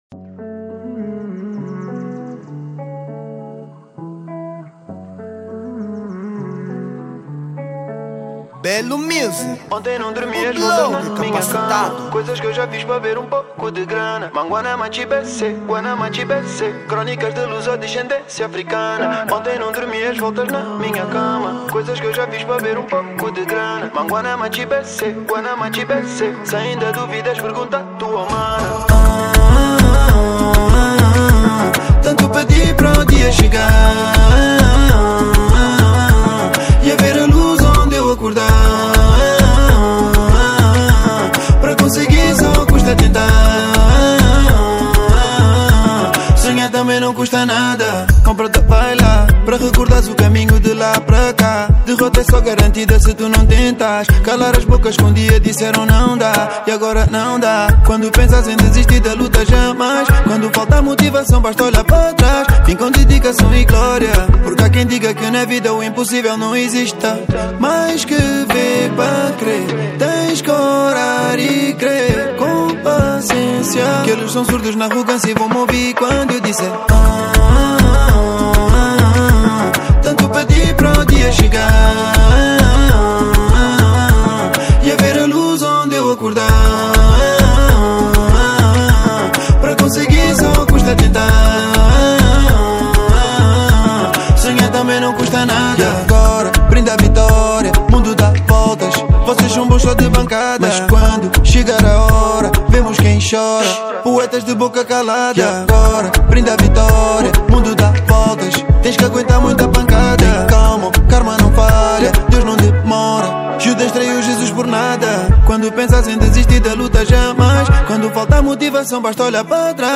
Dance Hill Formato ...